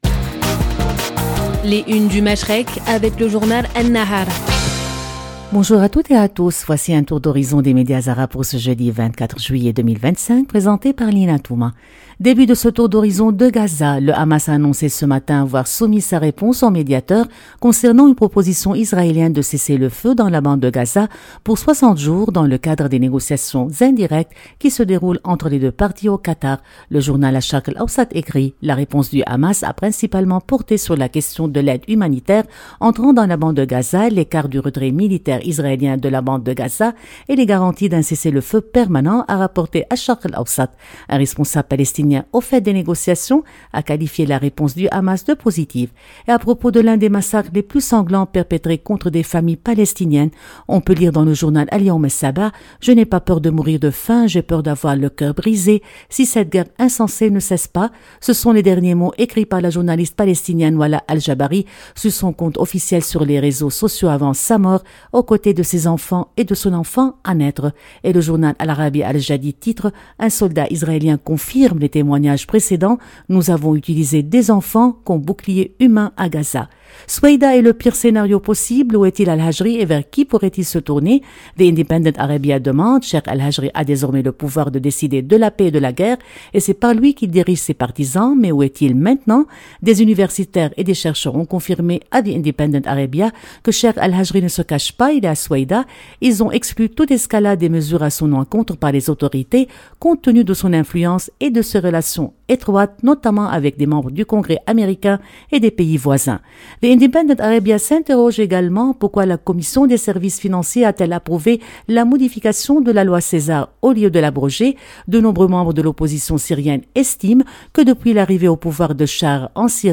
Revue de presse des médias arabes du 24 juillet,2025